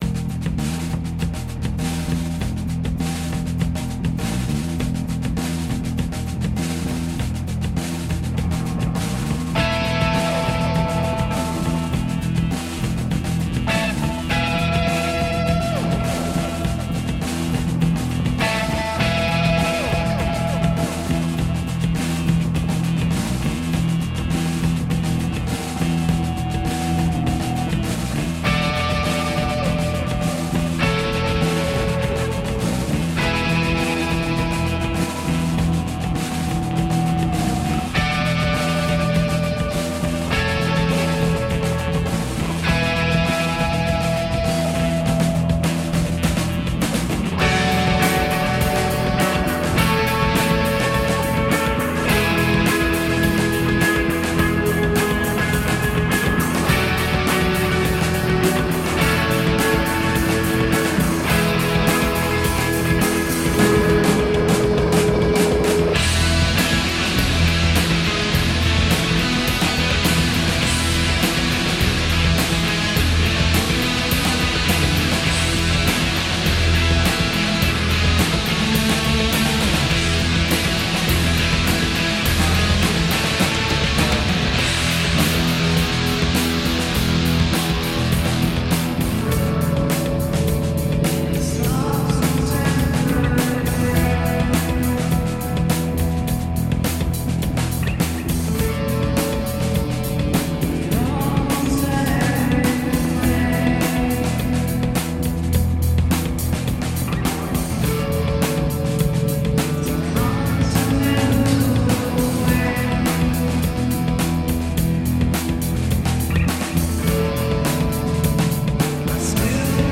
An arty thump of melody and bombast.